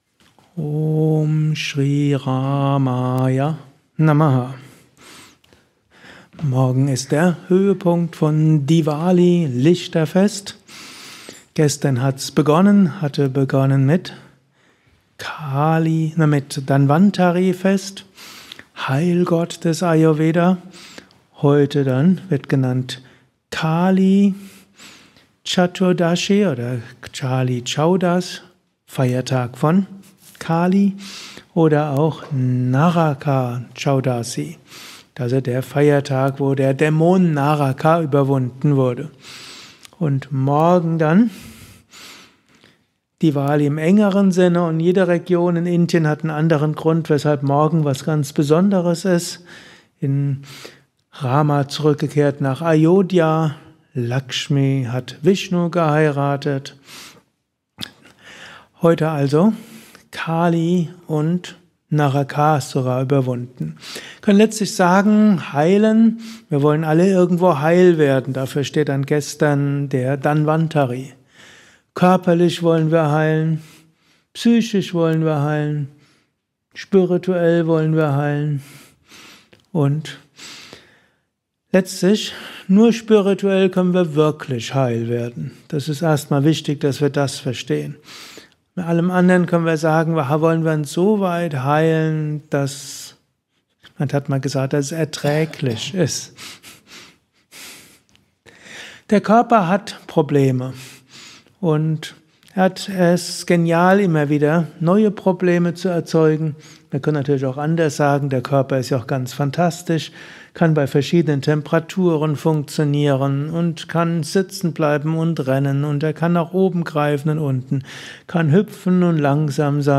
Dies ist ein kurzer Vortrag als Inspiration für den heutigen Tag
während eines Satsangs gehalten nach einer Meditation im Yoga